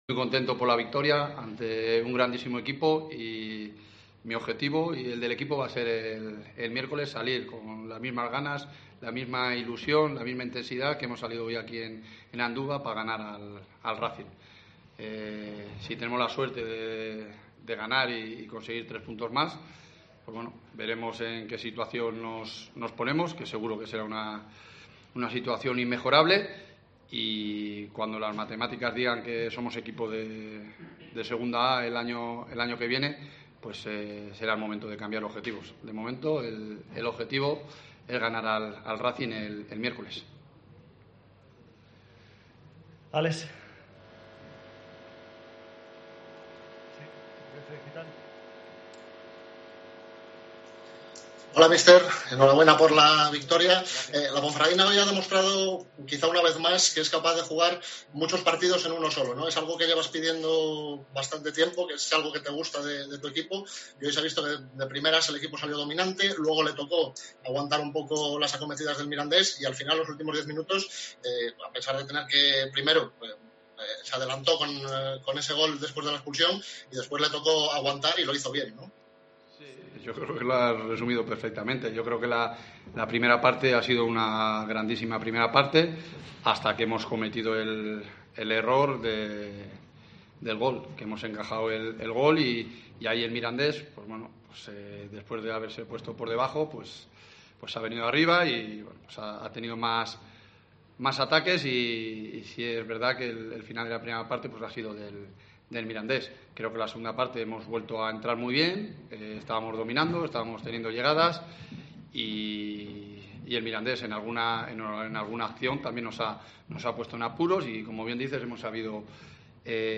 POSTPARTIDO SEGUNDA A J.34